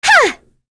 Cleo-Vox_Attack1.wav